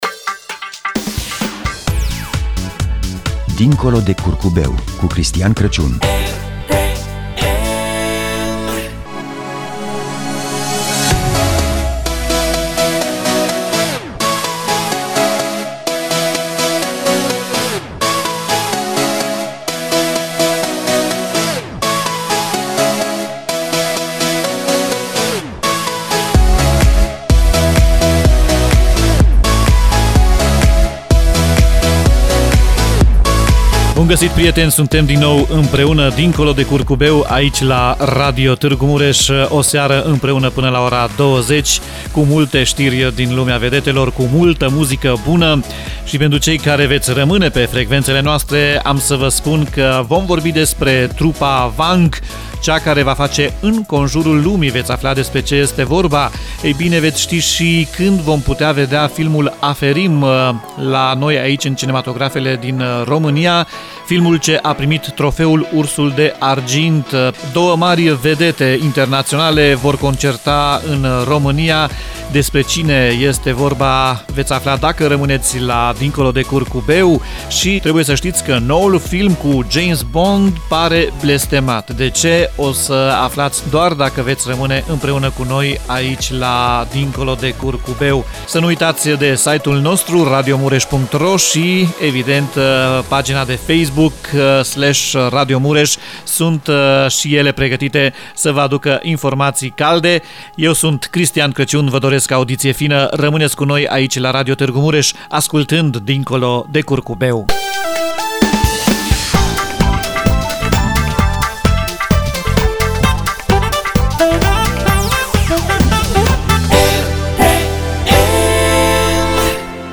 promo-dincolo-de-curcubeu.mp3